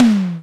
TOM71.wav